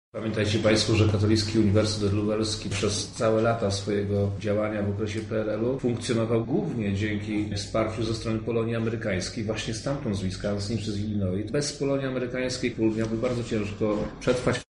Dobrze że takie wydarzenie odbywa się na Katolickim Uniwersytecie Lubelskim – mówi wojewoda lubelski Przemysław Czarnek.